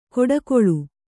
♪ koḍakoḷu